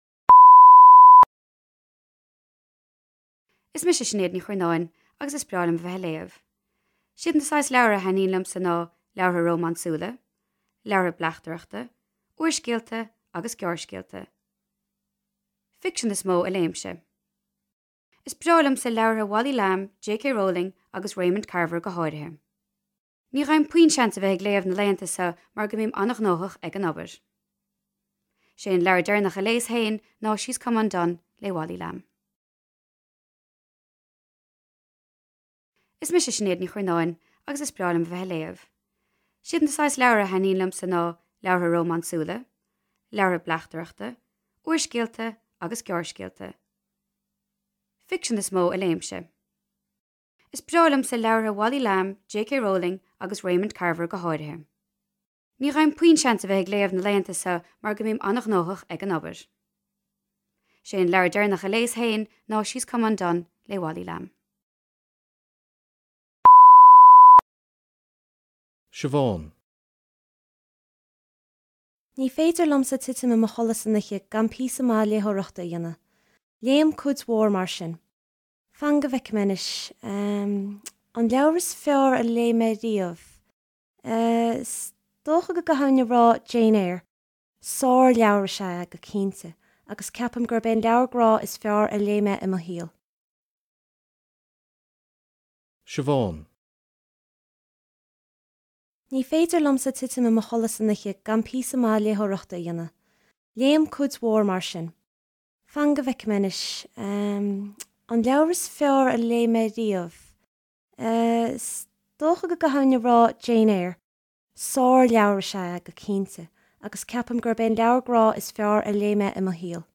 Sna cleachtaí seo, beidh na foghlaimeoirí ag éisteacht le daoine ag labhairt faoi na leabhair a thaitníonn leo.